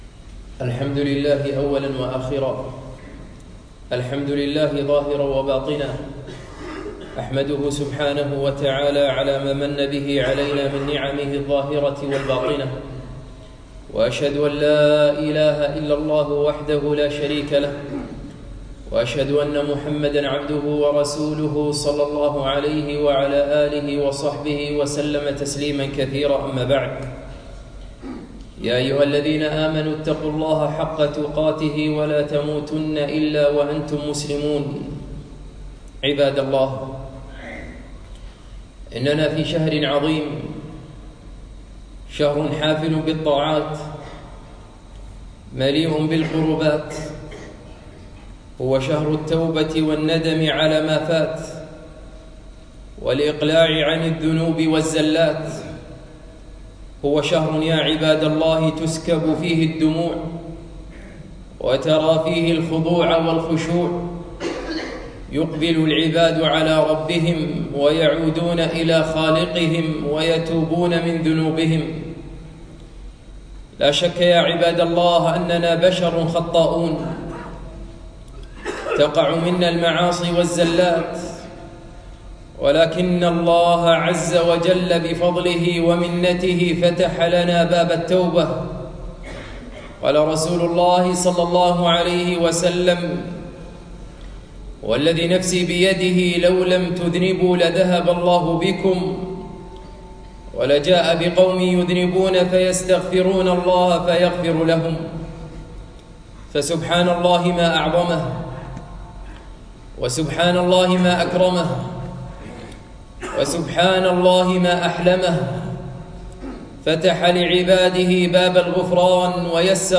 خطبة - شهر الصيام والتوبة